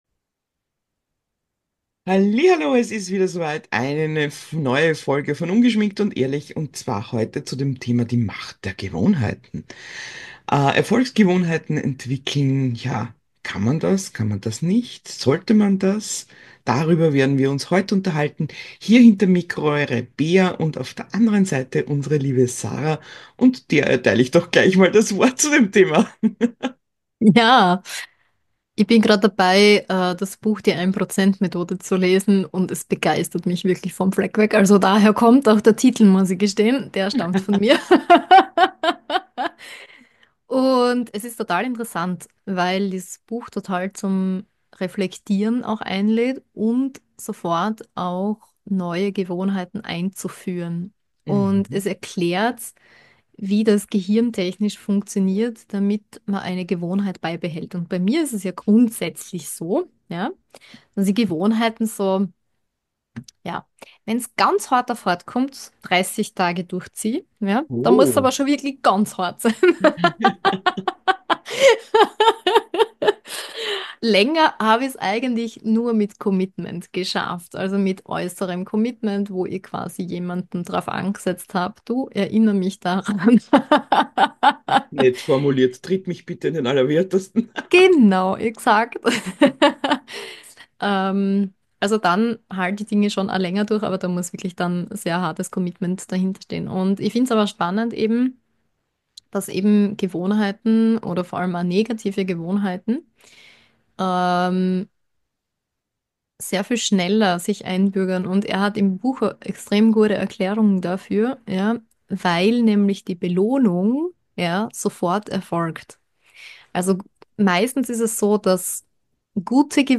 Wir sprechen zum Thema allgemein, wie man Gewohnheiten etabliert, plaudern aber auch über reale Beispiele aus unserem Leben und Business. Eine äußerst humorvolle Folge mit Side stepp zum Thema Gaming und Spielesucht…